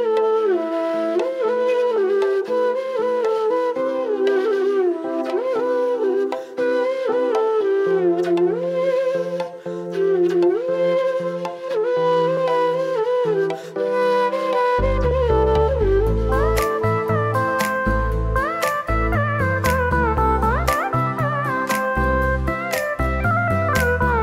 This is an instrumental parted ringtone.